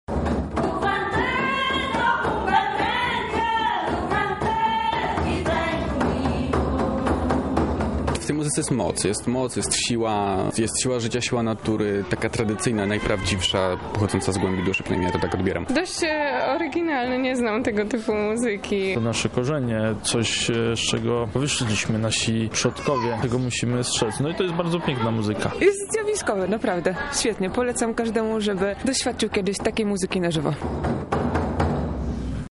Swoimi wrażeniami z festiwalu podzieli się z nami widzowie.